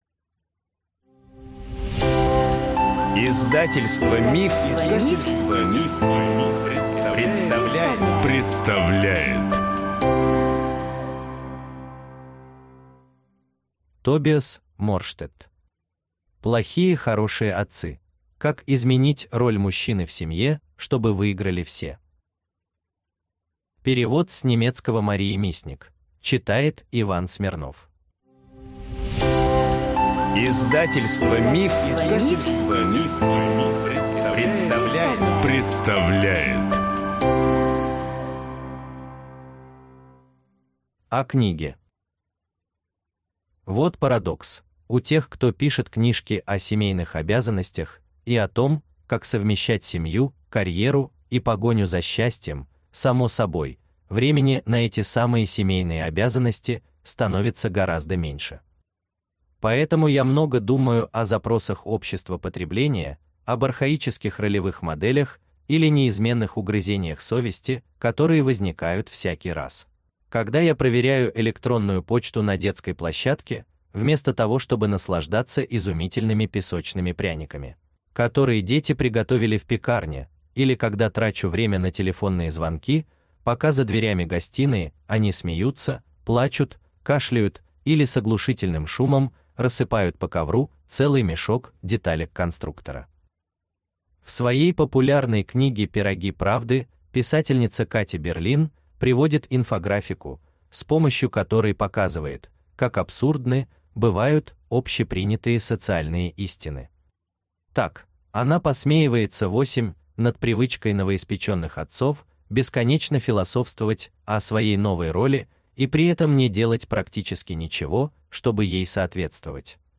Аудиокнига Плохие хорошие отцы. Как изменить роль мужчины в семье, чтобы выиграли все | Библиотека аудиокниг